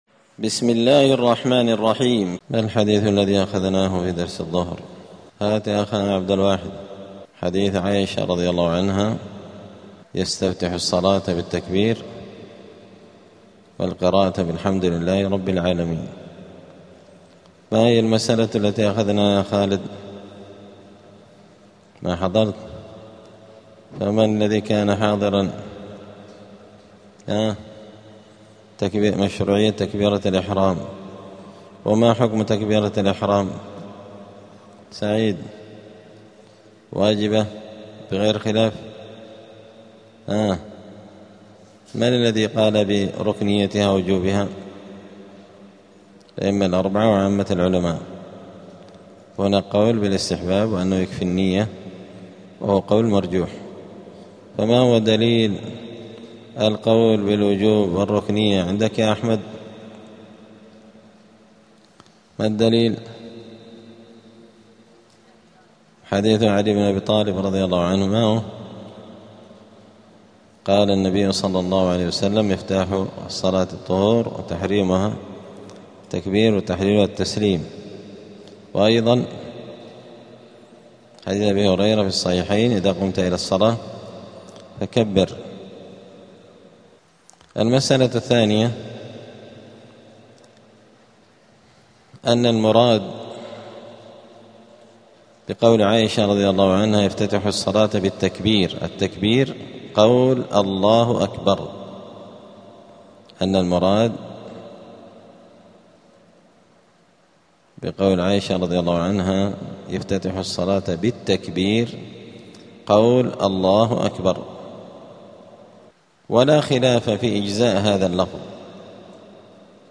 دار الحديث السلفية بمسجد الفرقان قشن المهرة اليمن
*الدرس السابع والسبعون بعد المائة [177] باب صفة الصلاة {حكم تكبيرة الإحرام والانتقال}*